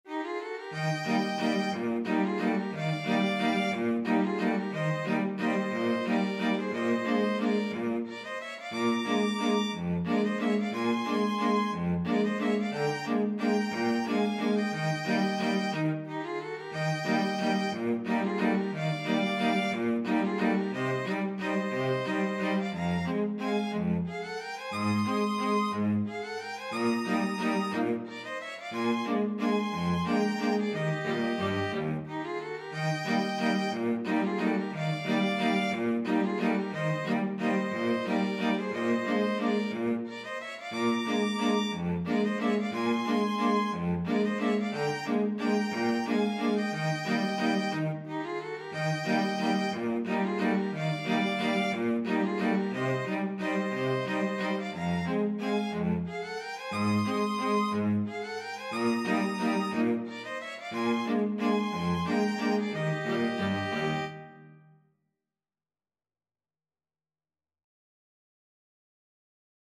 One in a bar .=c.60
3/4 (View more 3/4 Music)
Film (View more Film String Quartet Music)